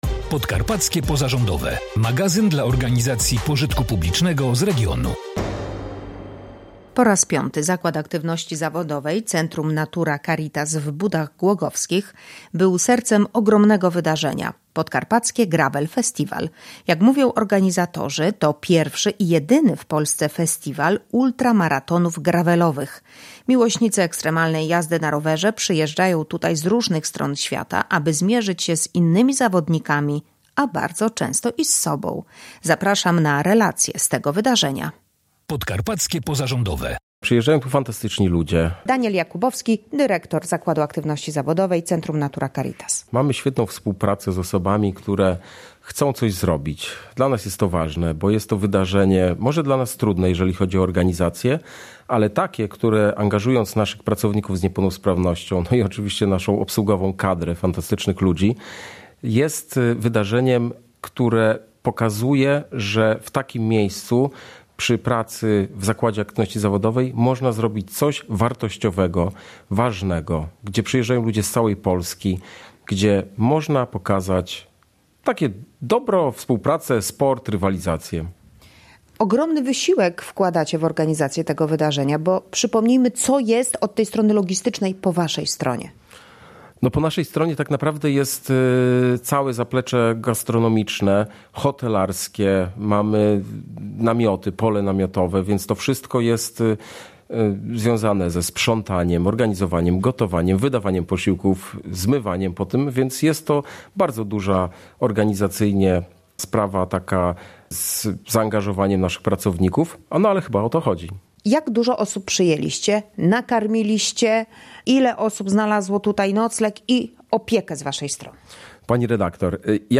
Po raz piaty Zakład Aktywności Zawodowej Centrum Natura Caritas w Budach Głogowskich był sercem ogromnego wydarzenia: Podkarpackie Gravel Festival. Jak mówią organizatorzy, to pierwszy i jedyny w Polsce festiwal ultramaratonów gravelowych. Miłośnicy ekstremalnej jazdy na rowerze przyjeżdżają tu z różnych stron świata, aby zmierzyć się z innymi zawodnikami, a bardzo często i z sobą.